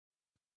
Аудиокнига Судьба человека | Библиотека аудиокниг